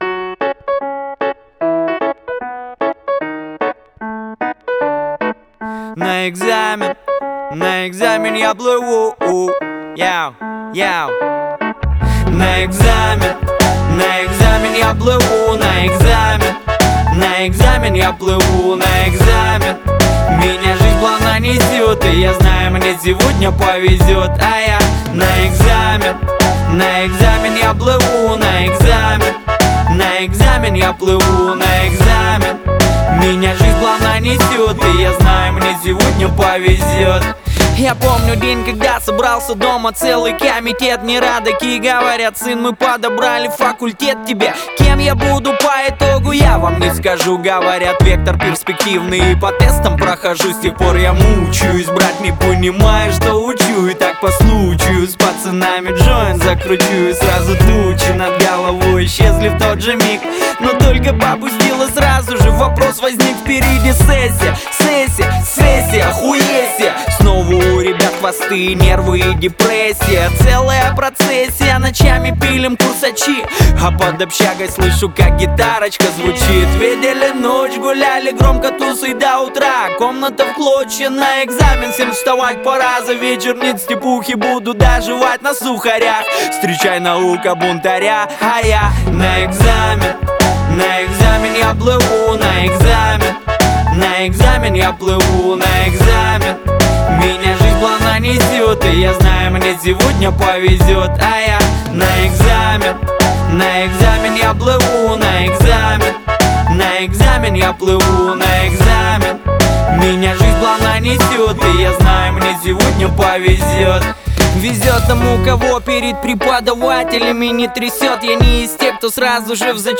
энергичная и задорная песня